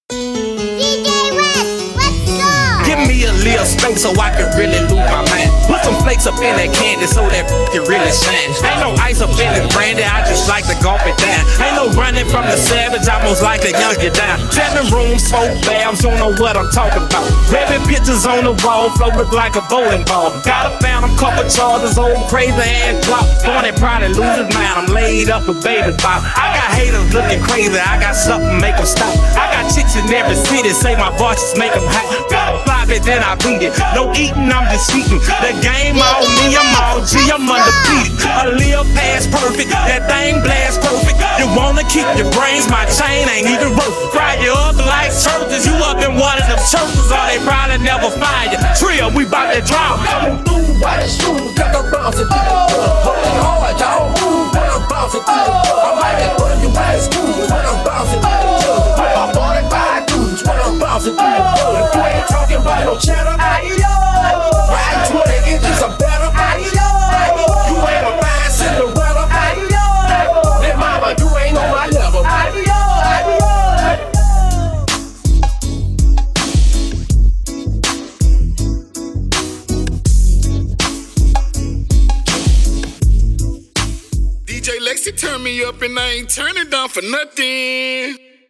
HipHop
RnB